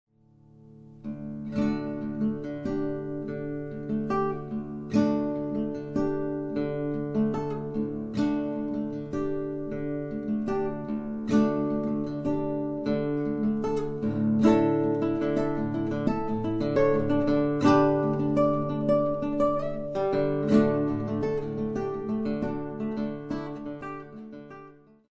fingerstyle guitar solos on 6 and 12 string guitars
solos on 6 and 12 strings, as well as ukulele